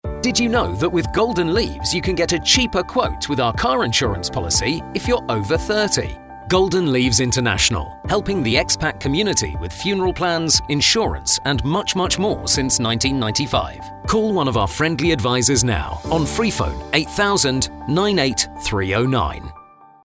Radio Adverts"Did You Know?..."
Golden-Leaves-APR18-Car-MALE-20.mp3